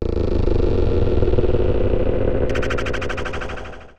Glitch FX 08.wav